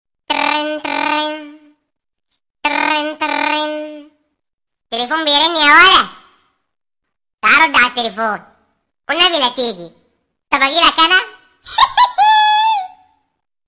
Description: phone ringing